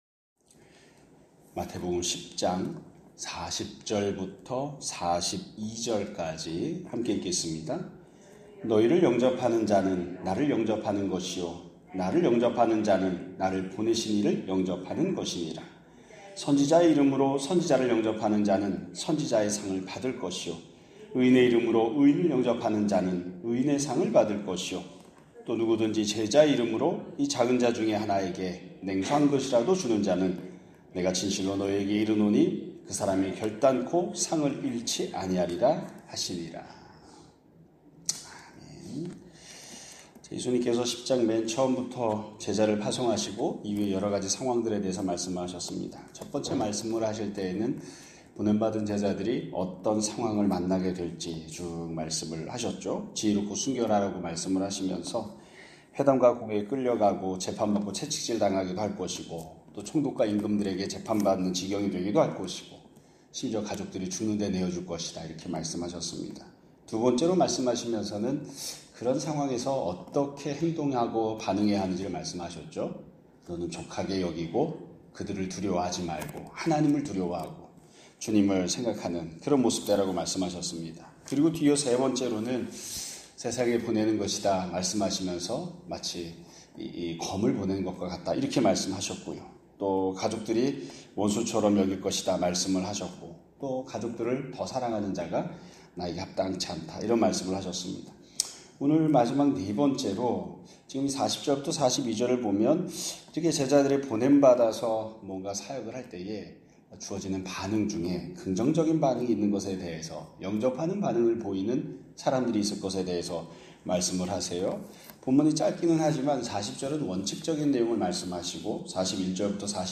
2025년 8월 20일 (수요일) <아침예배> 설교입니다.